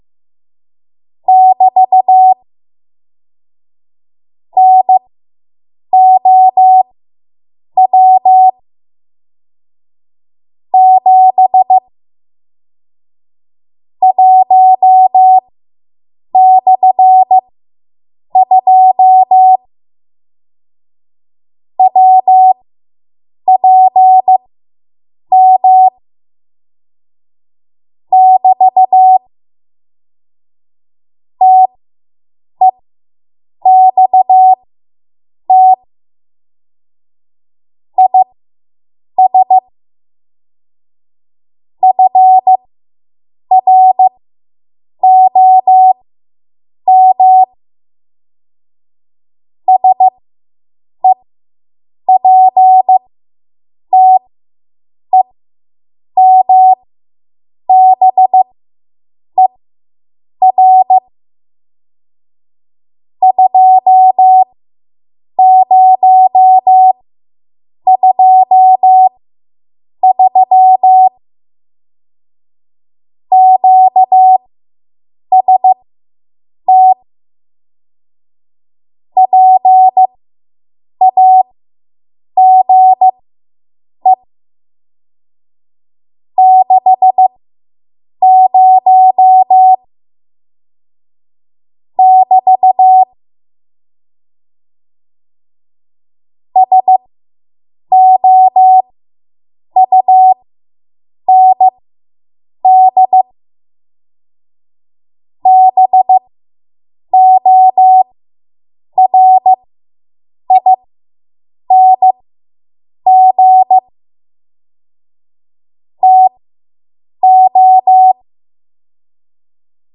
Code Practice Files
Please note the tone frequency is 750 Hz. Speeds 15 WPM and below, the character speed is 15 WPM Farnsworth. At 18 WPM and above, the speeds are standard.